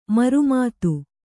♪ maru mātu